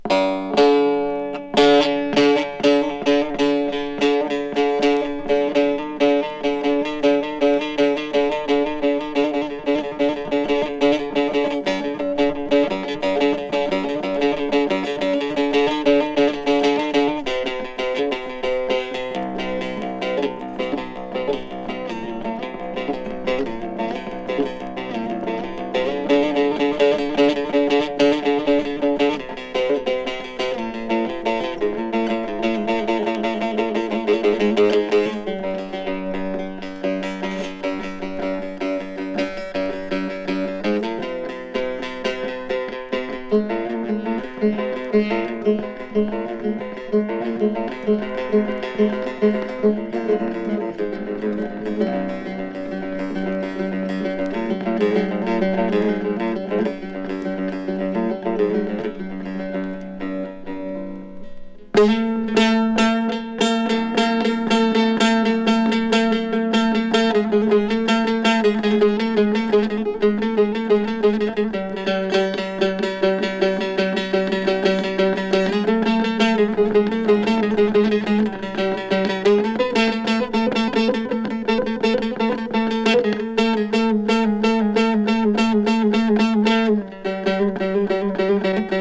詳細不明ですが、トルコをイメージして制作されたライブラリー音楽のようです。